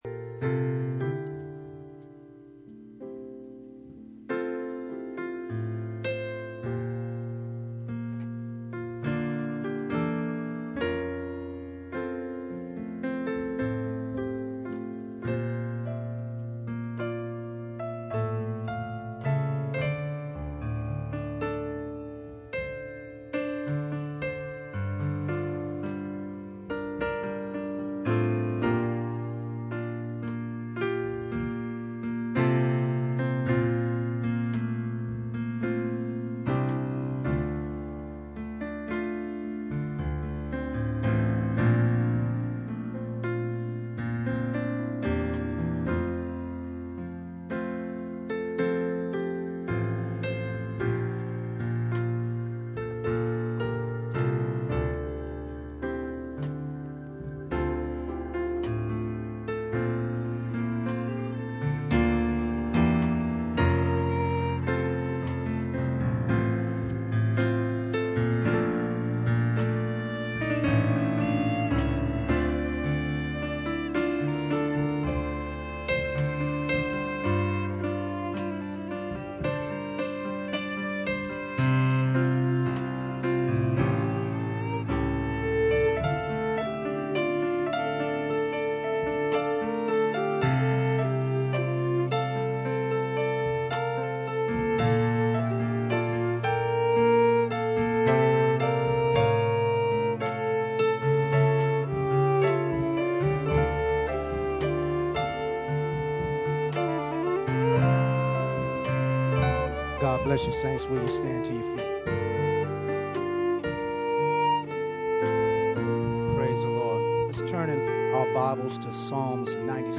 Series: Guest Minister
Ephesians 1:4 Service Type: Sunday Morning %todo_render% « Church Ages